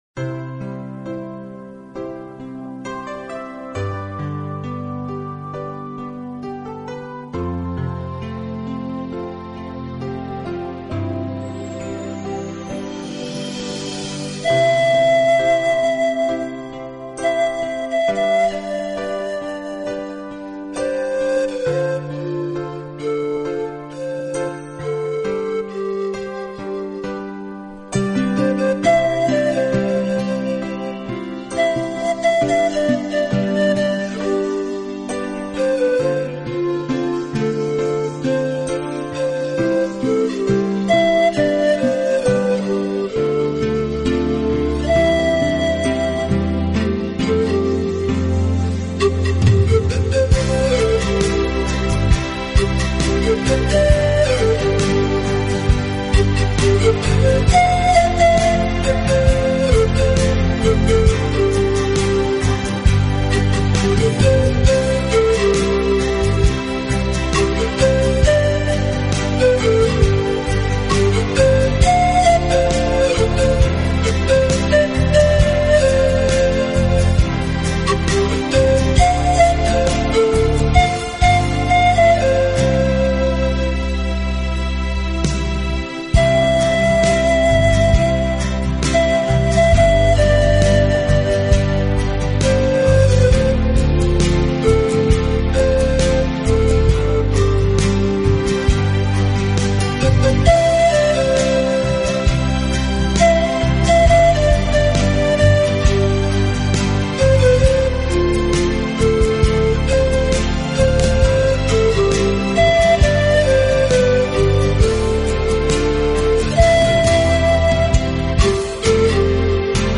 自然的乐器，充满了大自然奔放、和谐的意境，又具有一种太空般虚幻、飘渺的音色。